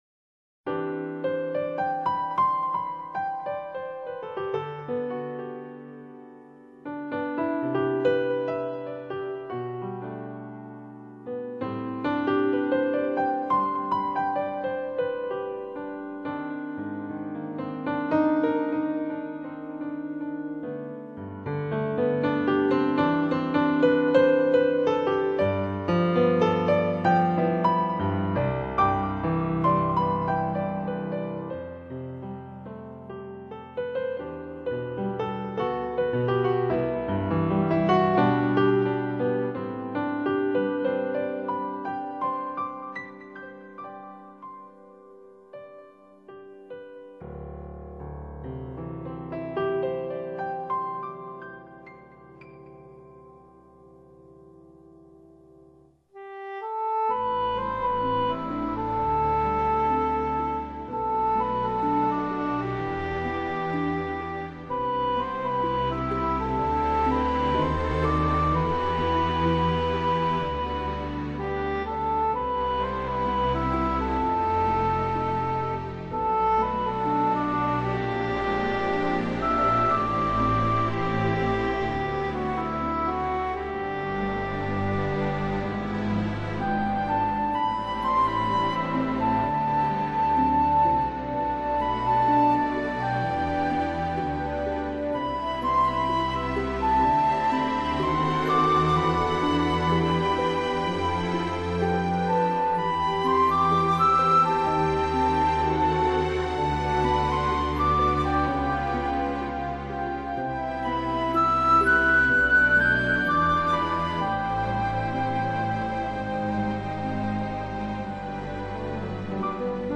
这张CD是依照自然声响「1/f摇晃」为基础制作而成的，是最的音乐补给品！
成分：依照自然声响「1/f摇晃」为基础制作而成。